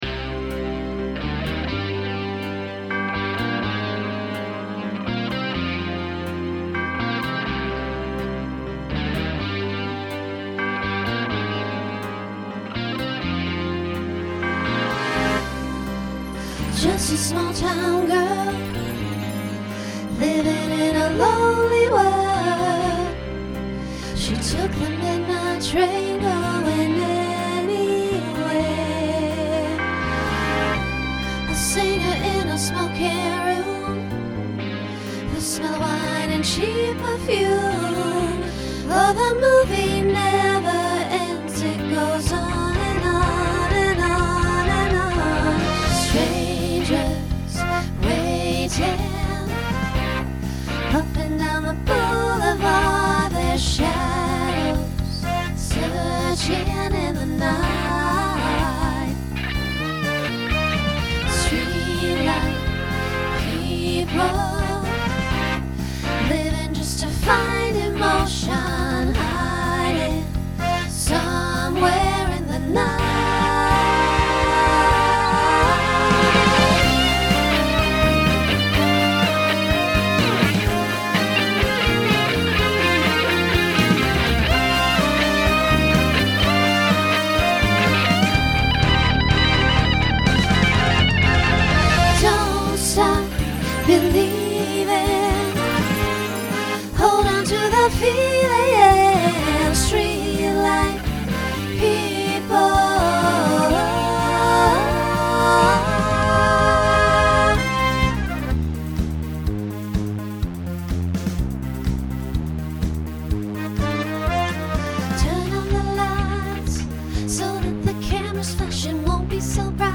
Genre Pop/Dance , Rock Instrumental combo
Voicing SSA